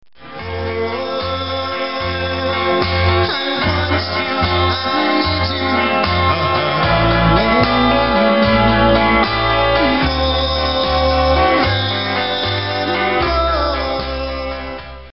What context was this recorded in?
STUDIO SAMPLES (approx. 15 seconds):